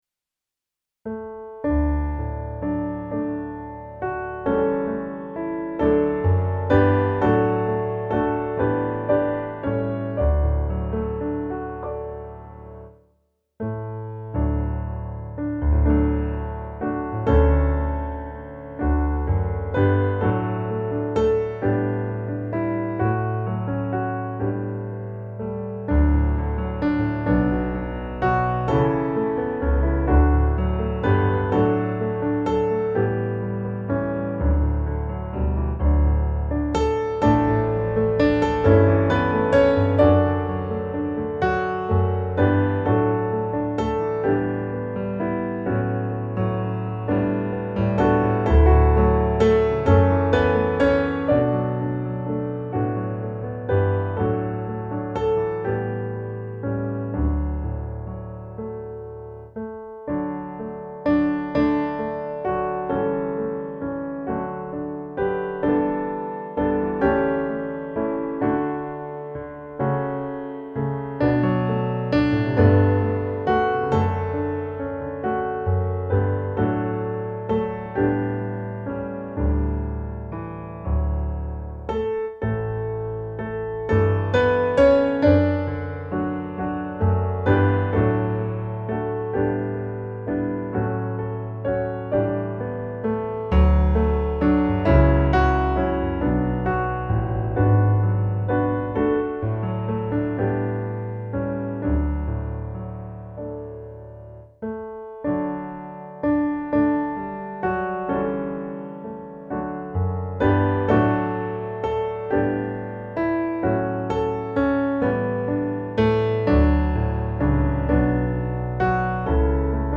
musikbakgrund
Gemensam sång
Musikbakgrund Psalm